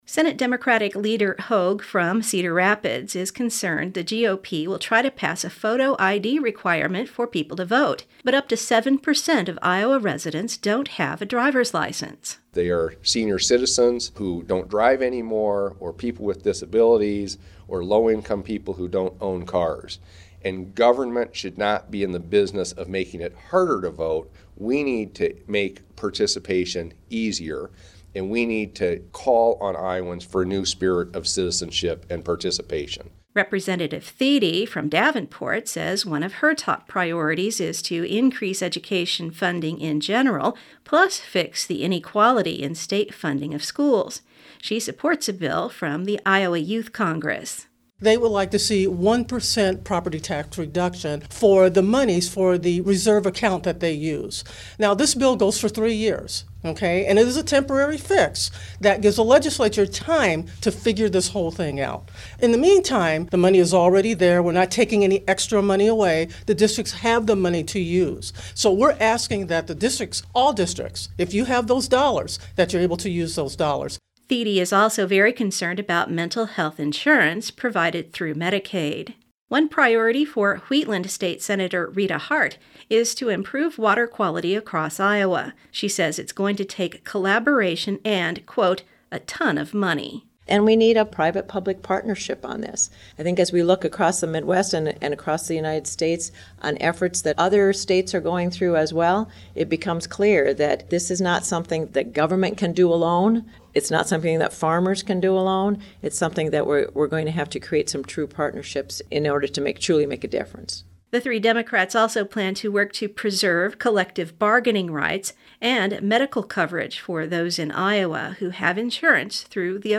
Radio story, podcast